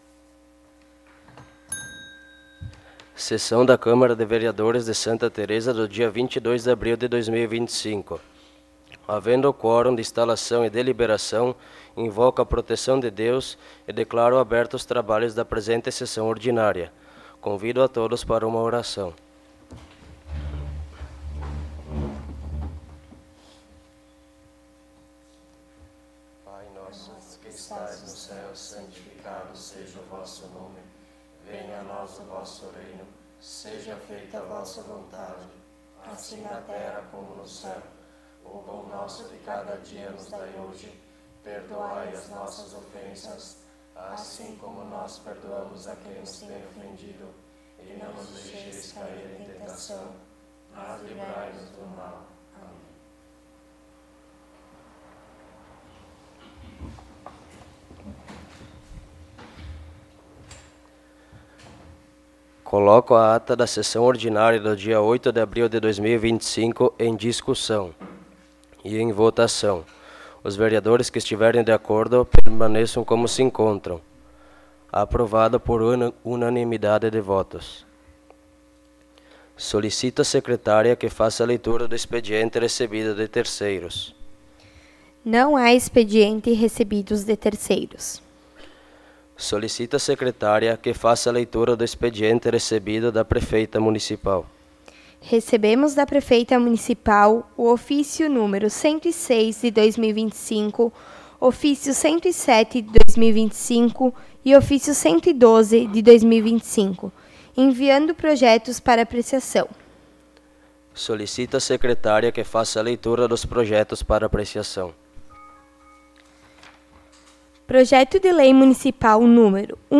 06° Sessão Ordinária de 2025
Áudio da Sessão